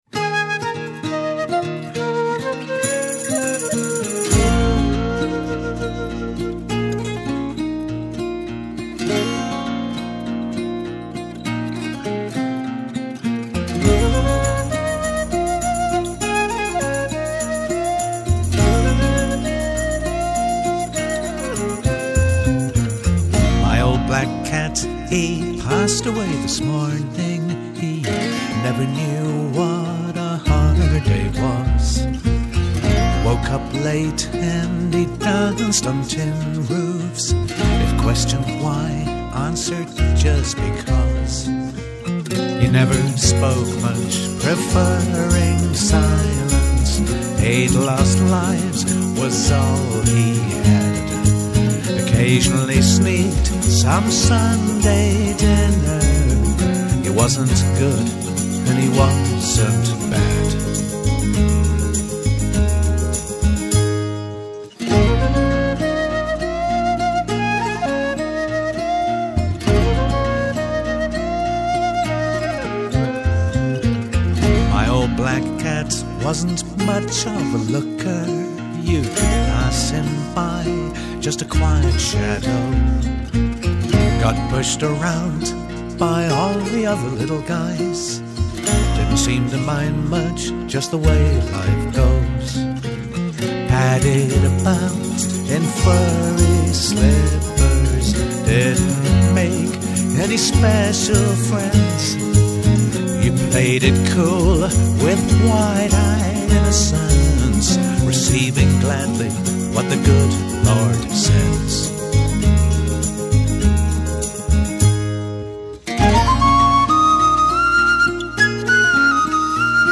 ART-ROCK